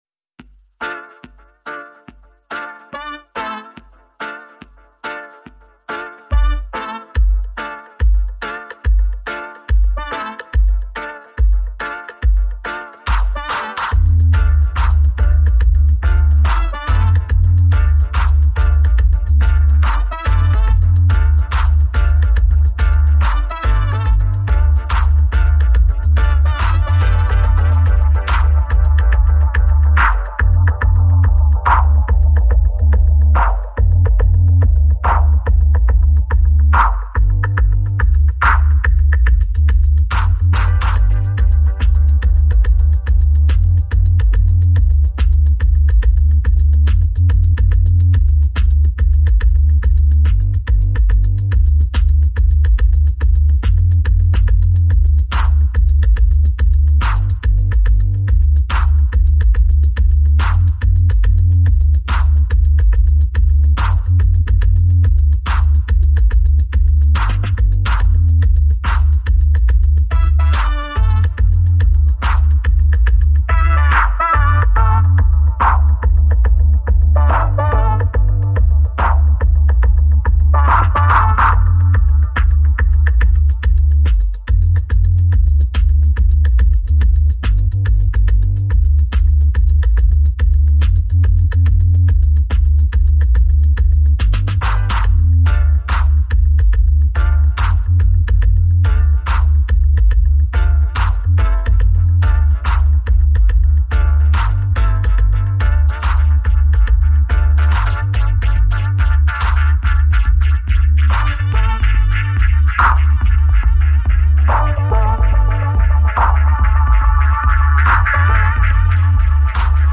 Early mixes with added SSS4 filter - Rough style!